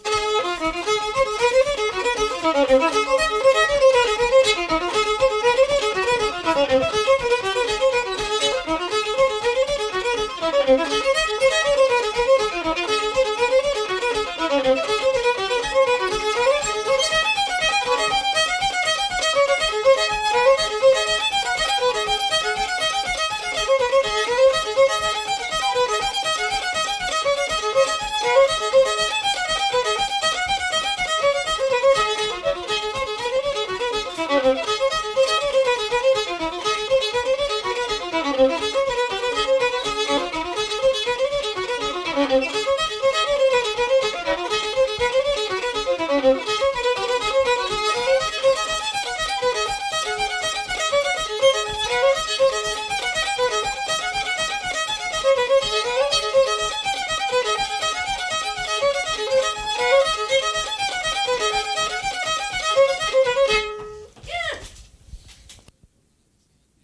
Unaccompanied